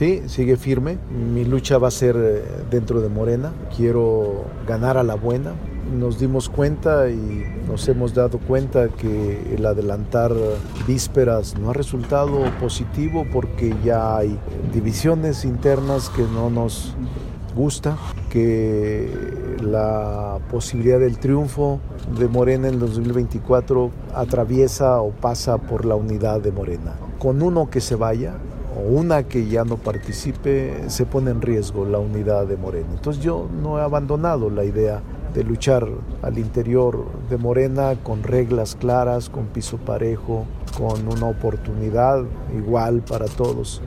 Entrevistado previo a la ceremonia de reconocimiento a la trayectoria del Club Deportivo Guadalajara Femenil organizada por el Senado, el presidente de la Junta de Coordinación Política refirió que diciembre pasado le sirvió para reflexionar.